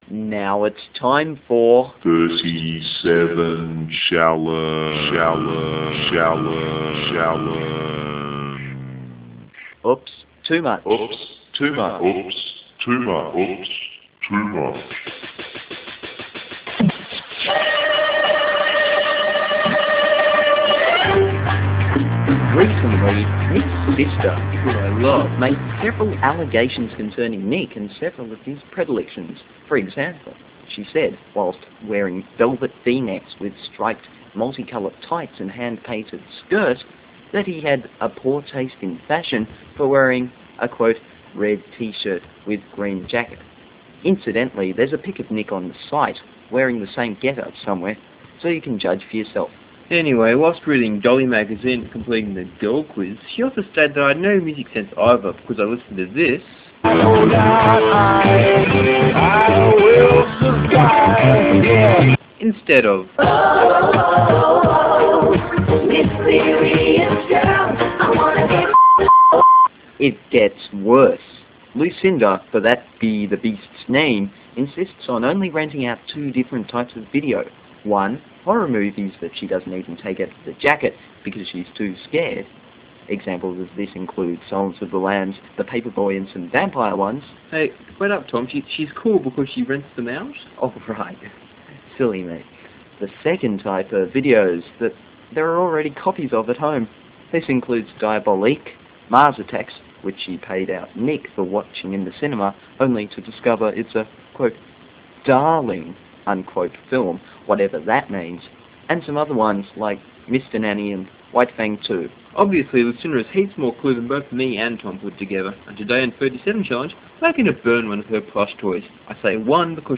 The proprietors of 37zone regret any offence caused by this sketch.